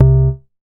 MoogLoFi 004.WAV